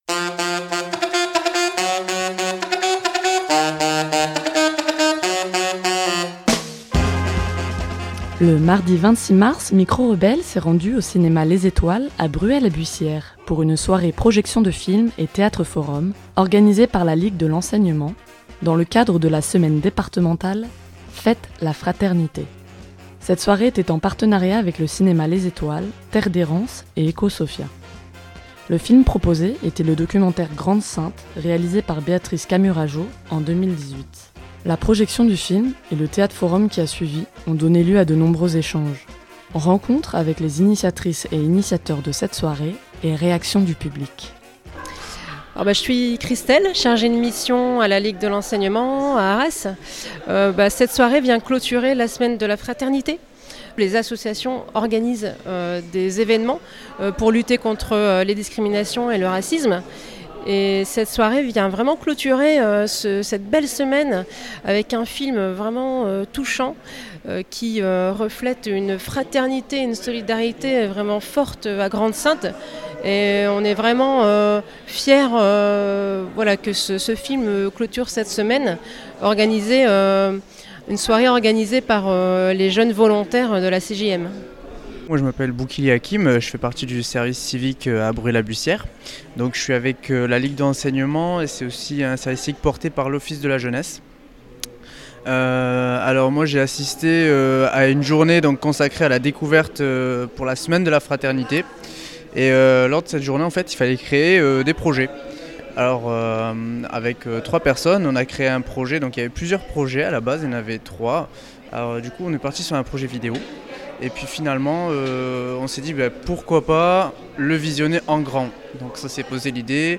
Mardi 26 mars, pour clôturer la semaine « Faites la fraternité », la Ligue de l’enseignement 62 organisait en partenariat avec le cinéma Les Étoiles de Bruay la Buissière, Terre d’errance et Ecosophia, une soirée projection et théâtre forum autour du documentaire Grande-Synthe de Béatrice Camurat Jaud.
La soirée a été riche en échanges et en interventions.